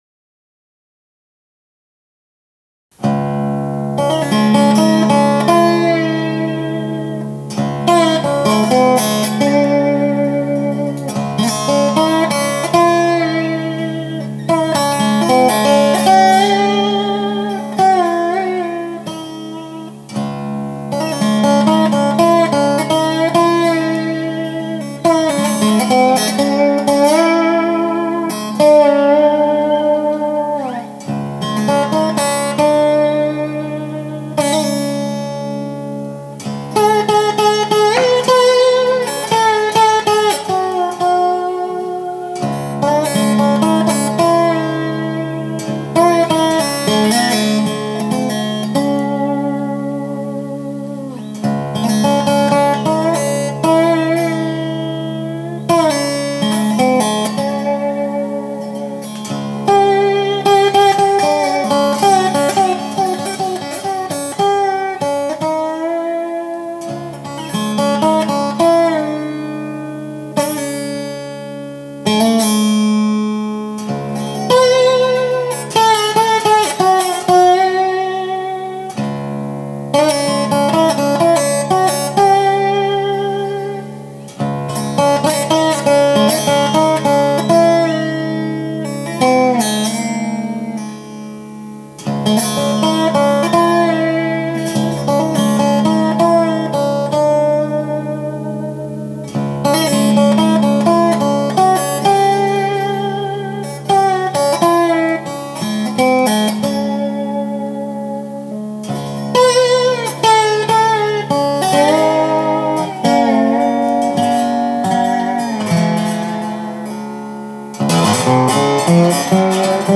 Having tried heavier bronze strings on my resonator, I found myself trying for something a little different in the way of a slide instrumental.
Just played into a microphone: nothing subtle in the way of manipulation, and I haven’t yet tried connecting it via its pickup. It’s a Gretsch Bobtail, if anyone cares…